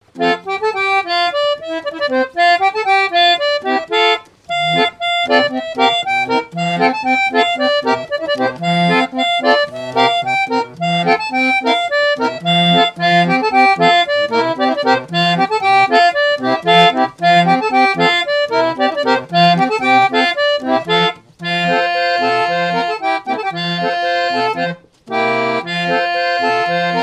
Suites de maraîchines sur orgue de barbarie
orgue de barbarie
maraîchine
musique mécanique